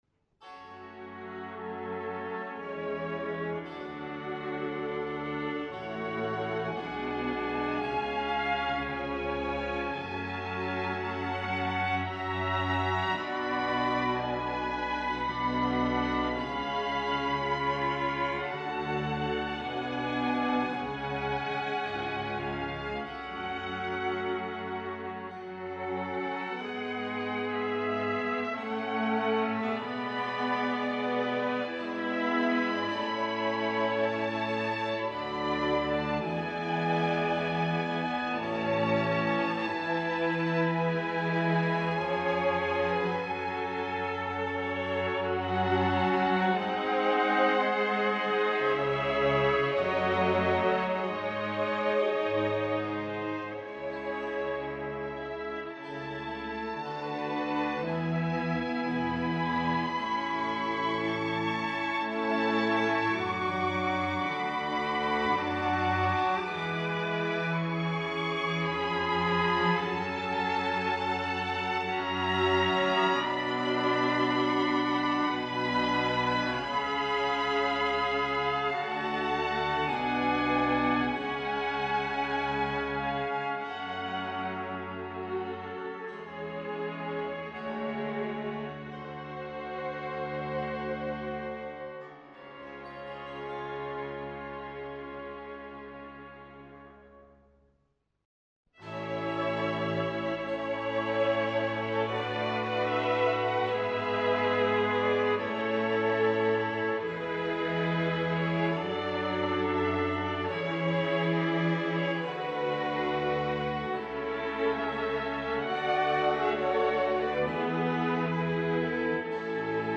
Adagio / Grave
in A Major